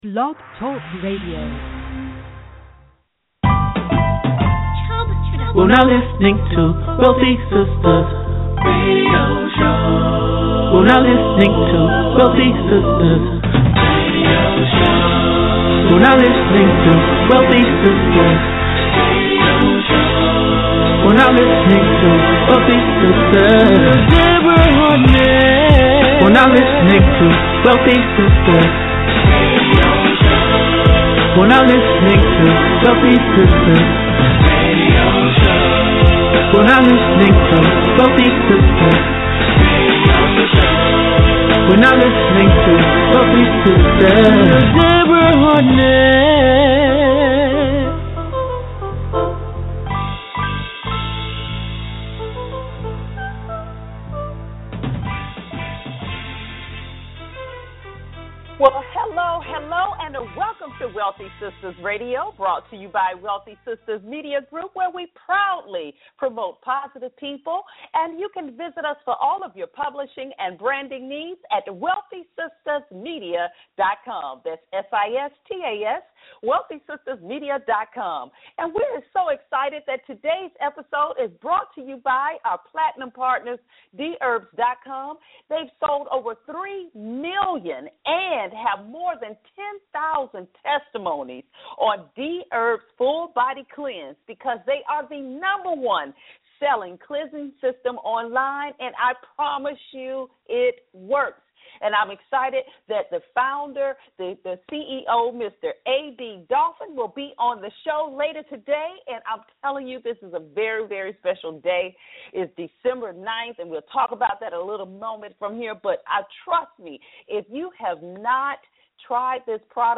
And is intertwined with some of the greatest R&B music of all time.